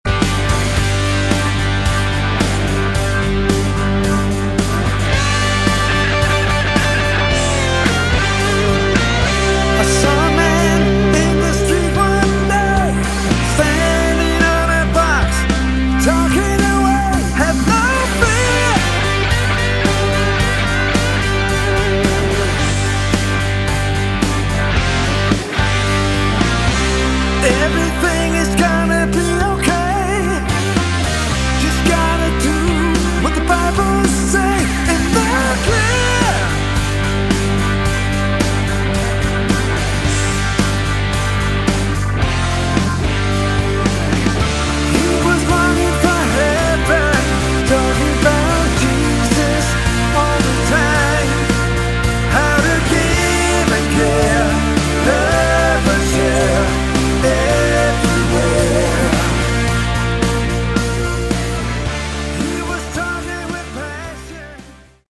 Category: Melodic Rock / AOR